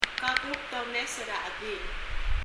発音　　英訳：